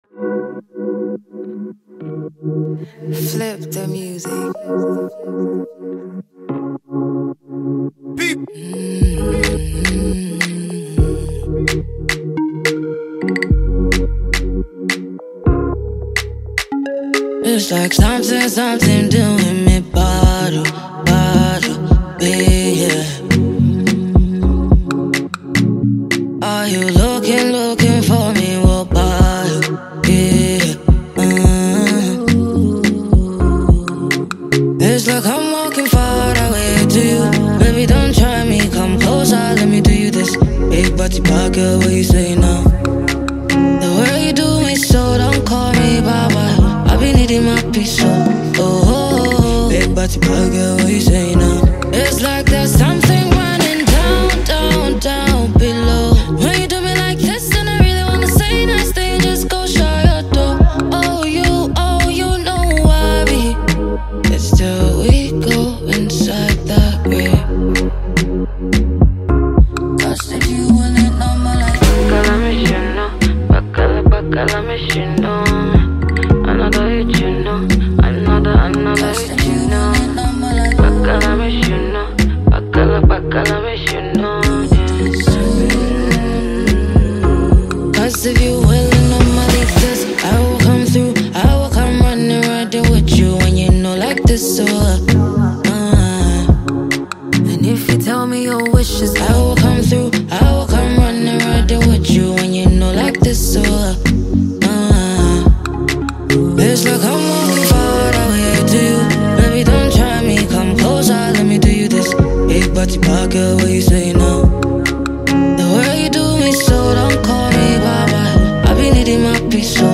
an infectious Afrobeats